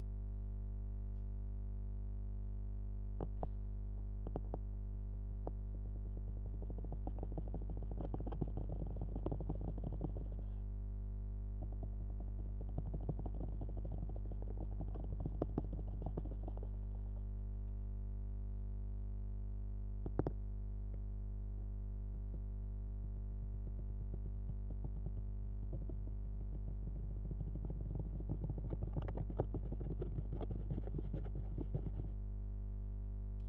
Test - contact mic, wall, through Mbox into Logic